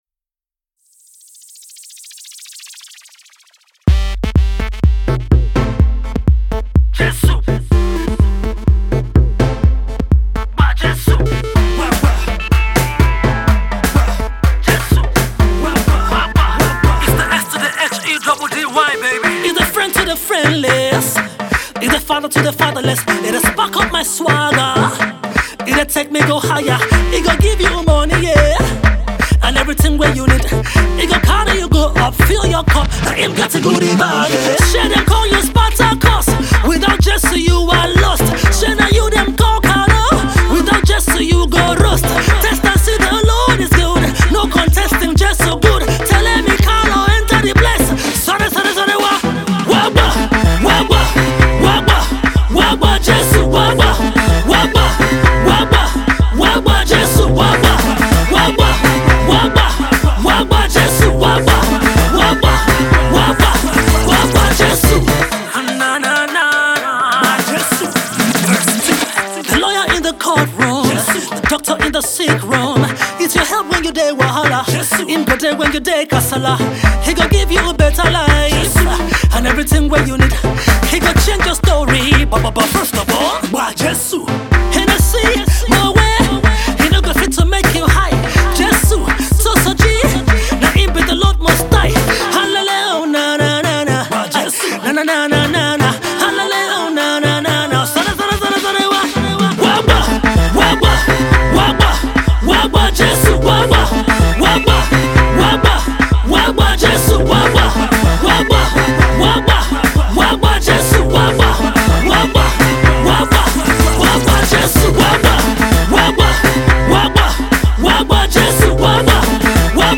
The street jam was produced